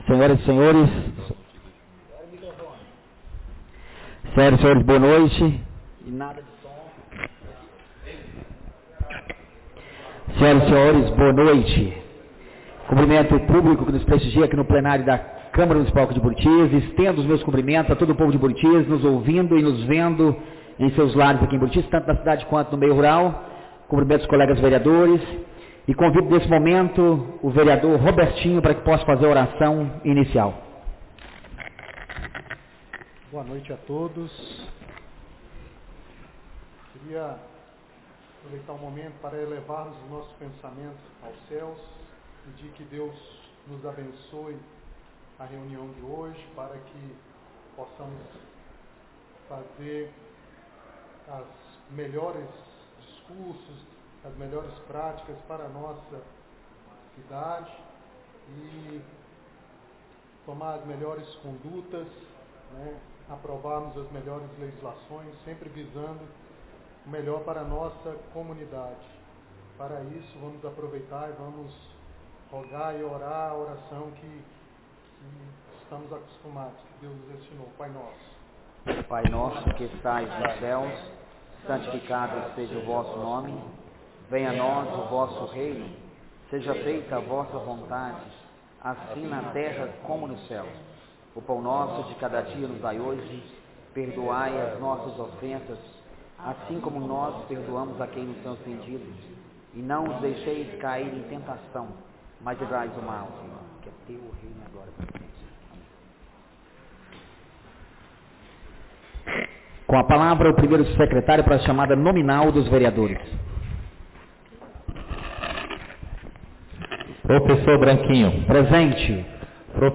2ª Reunião Ordinária da 2ª Sessão Legislativa da 16ª Legislatura - 09-02-26